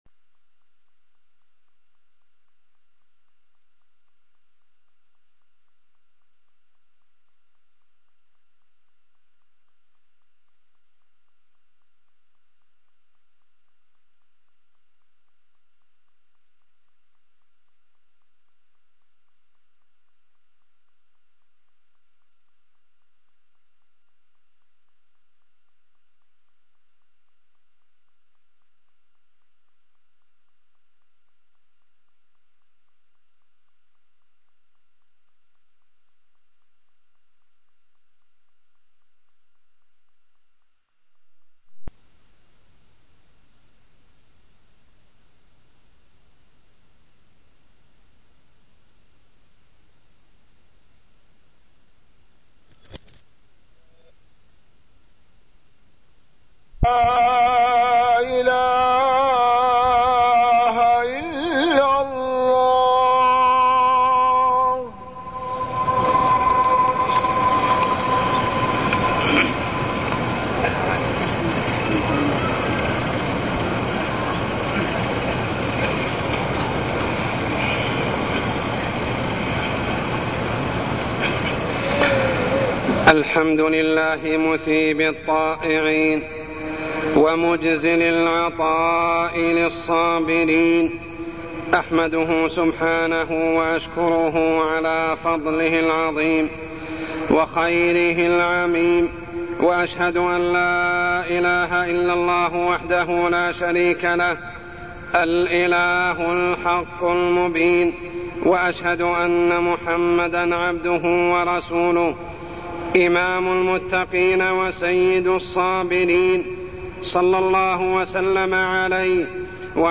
تاريخ النشر ١٢ ذو القعدة ١٤٢٠ هـ المكان: المسجد الحرام الشيخ: عمر السبيل عمر السبيل فضل الصبر